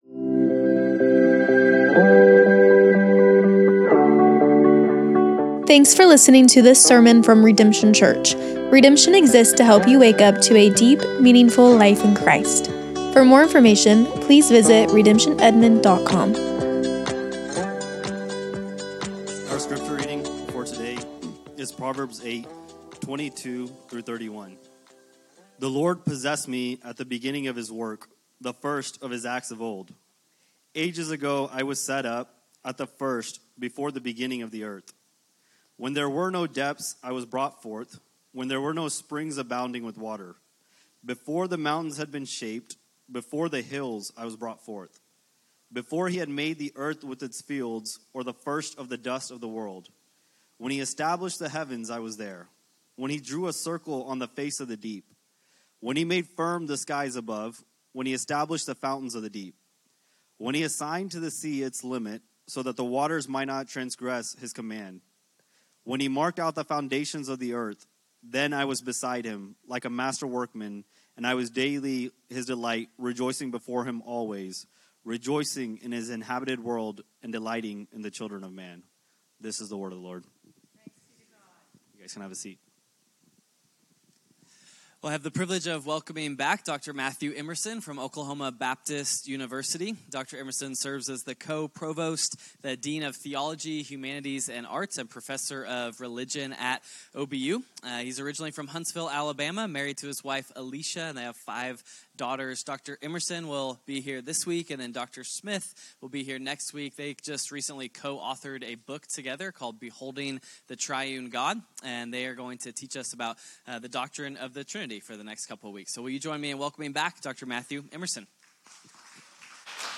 Redemption Church - Sermons